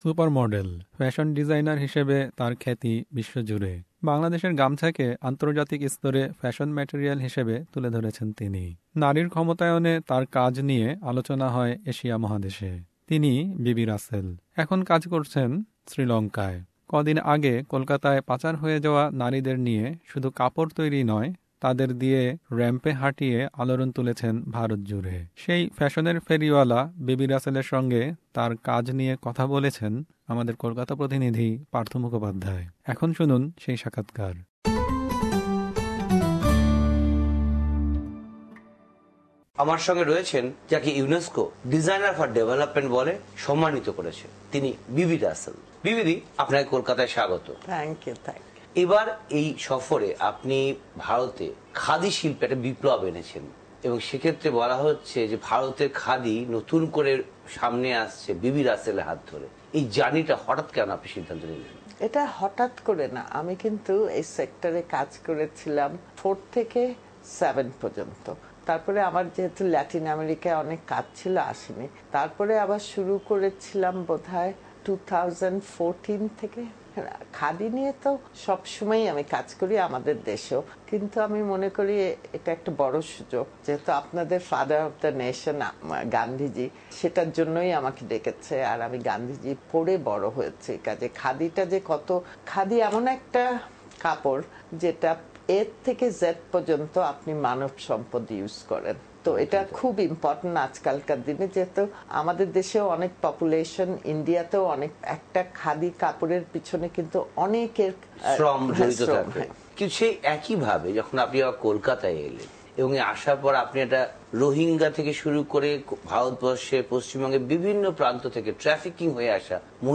Listen to Bibi Russell's full interview (in Bangla) with SBS Bangla in the audio player above.